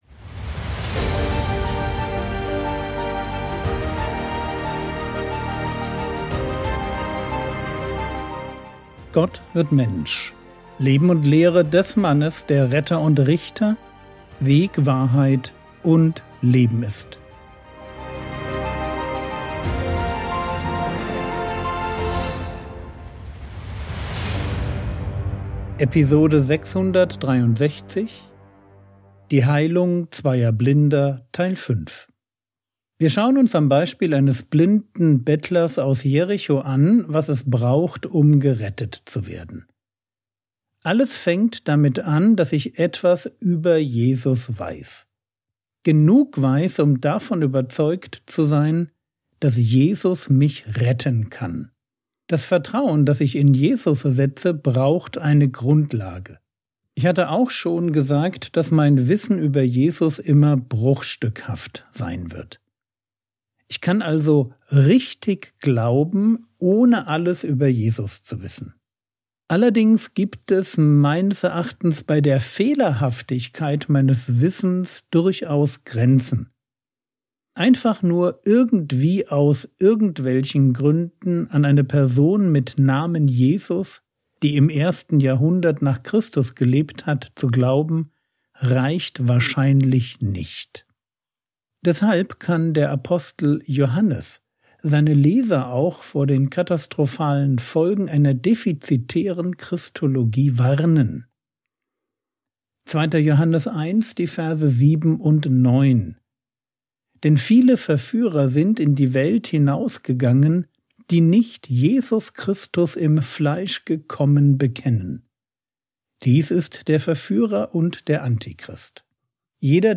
Episode 663 | Jesu Leben und Lehre ~ Frogwords Mini-Predigt Podcast